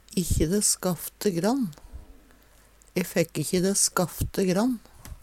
ein si ofte "inkje" i staden for ikkje